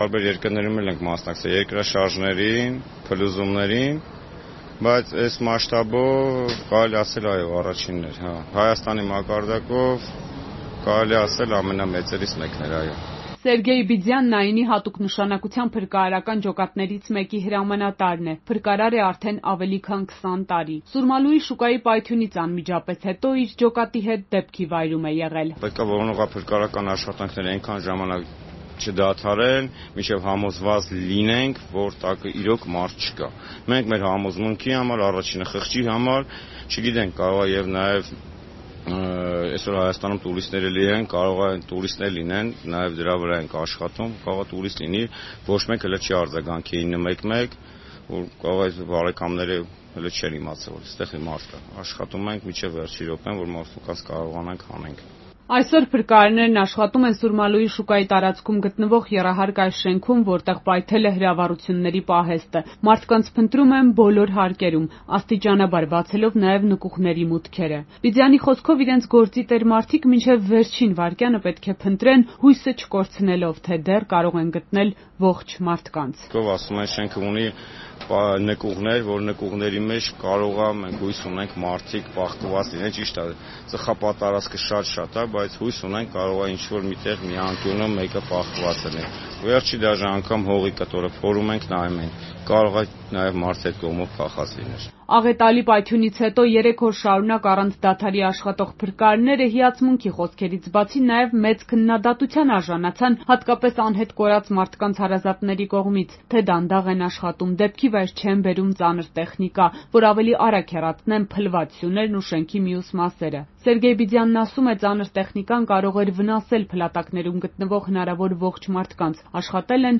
«Մեր խղճի հանդեպ մաքուր ենք, արել ենք մեր աշխատանքը, ոչ մի րոպե չենք քնել ու վարանել». փրկարար
Ռեպորտաժներ